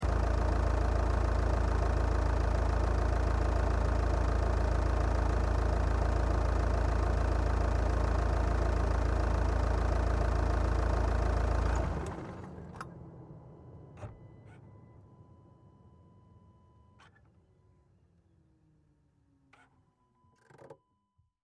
Звуки Audi A4
Звук работы дизельного двигателя Audi A4 с последующей заглушкой